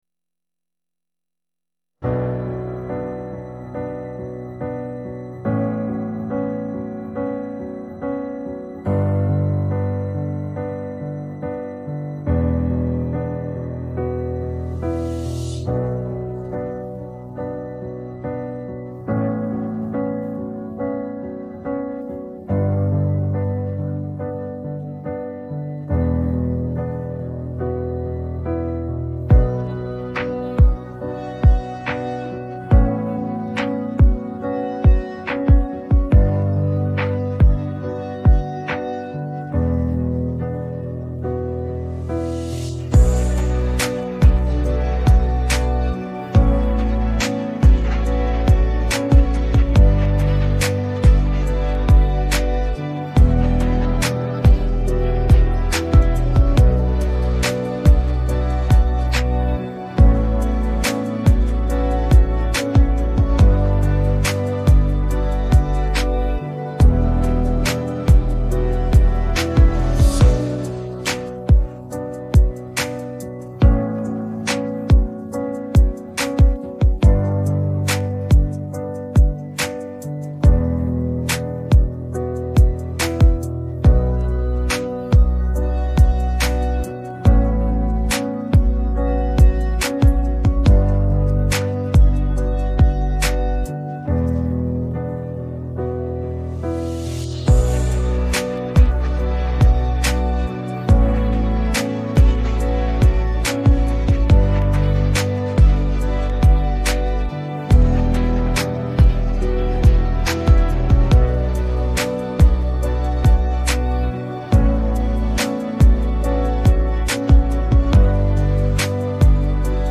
Bakgrunnsmusikk